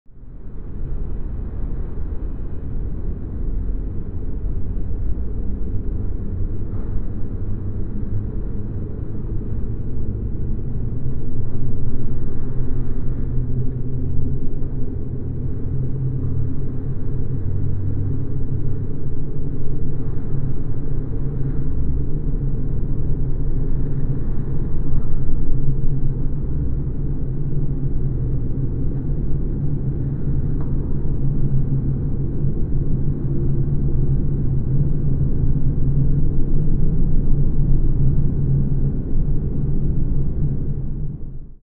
Spaceship Engine Room Hum Ambient Sound Effect
Experience a sci-fi spaceship engine room hum with deep mechanical drones and a futuristic atmosphere as the ship moves through space.
Bring your audience inside a working spacecraft in motion with a steady, realistic engine ambience.
Spaceship-engine-room-hum-ambient-sound-effect.mp3